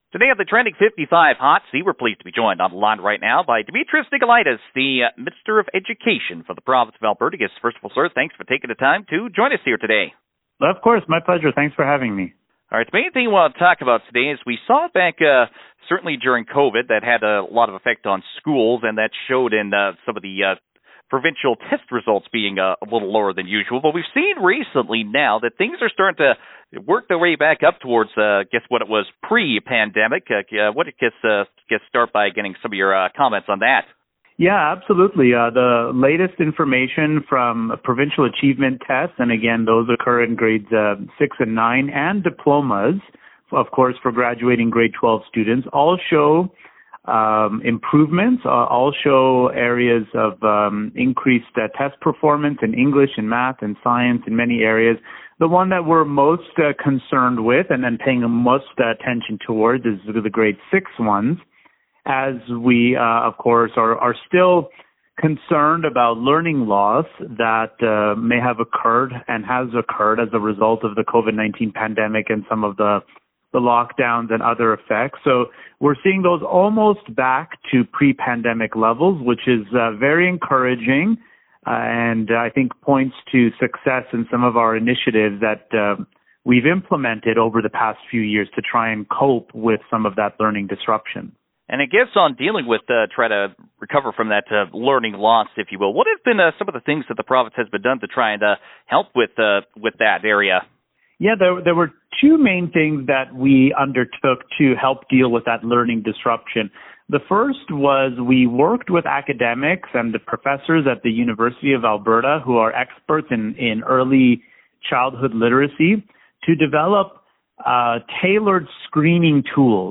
Education Minister Demetrios Nicolaides says the development of some screening tools provided some important data on what areas children were struggling in.